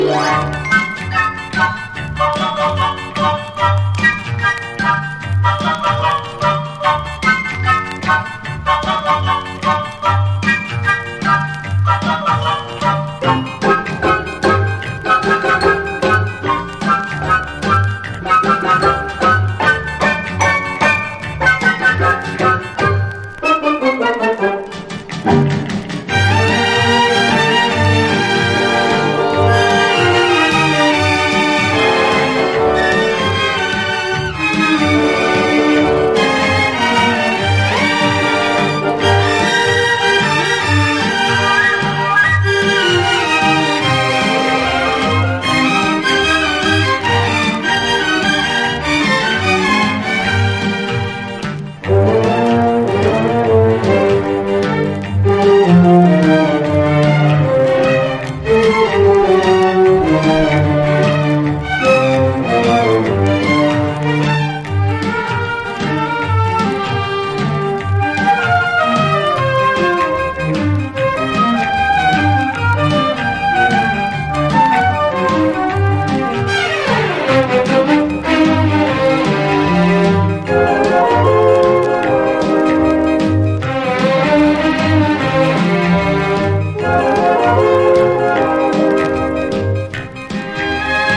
とてつもなく優美でロマンティックな魅惑のストリングス・ラウンジ！
ピカピカと眩しい音粒が弾け飛ぶ